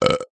burp3.ogg